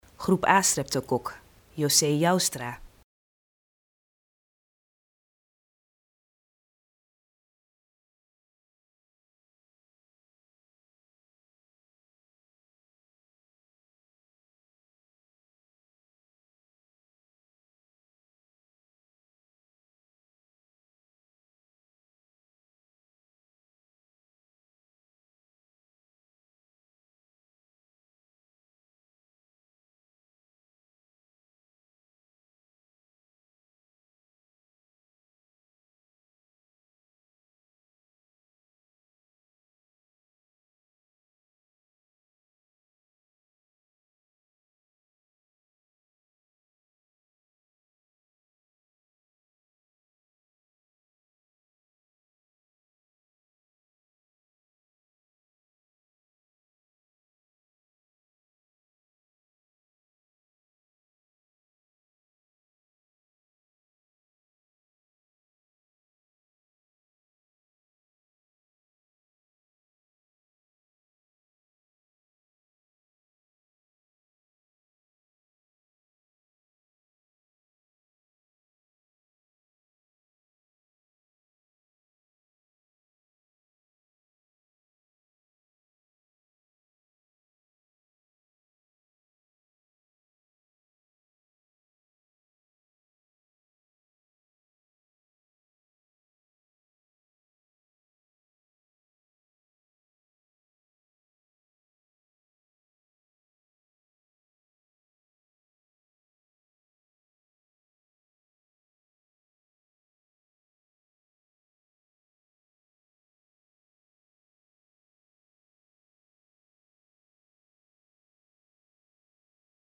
In deze video wordt u door een huisarts uitgelegd wat de groep A streptokok is, hoe u het kan voorkomen en wat u moet doen als u deze ziekte heeft.